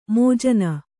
♪ mōjana